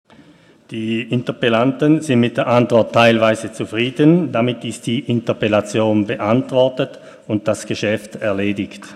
25.11.2019Wortmeldung
Session des Kantonsrates vom 25. bis 27. November 2019